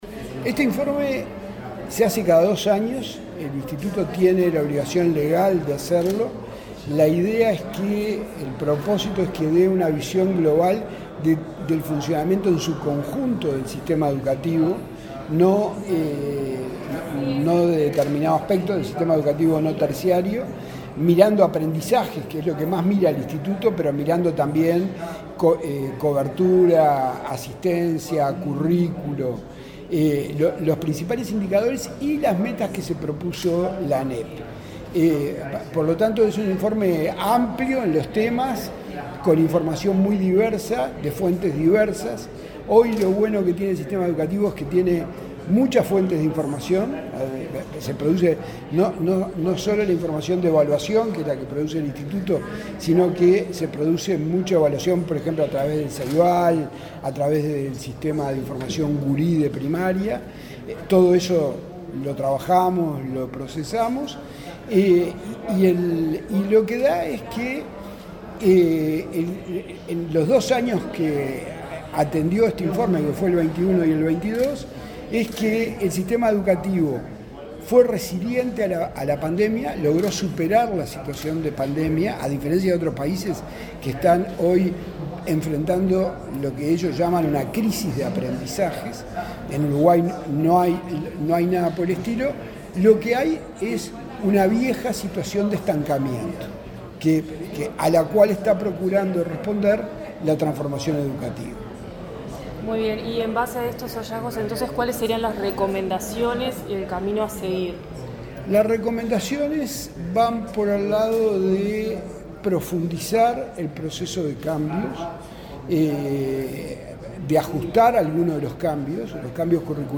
Entrevista al presidente del Ineed, Javier Lasida